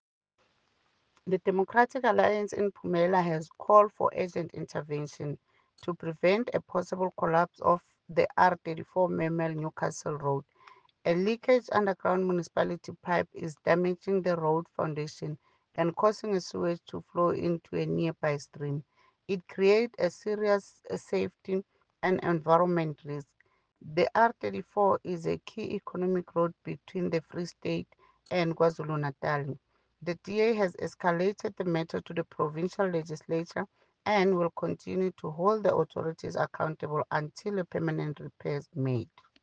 English soundbite by Cllr Ntombi Mokoena,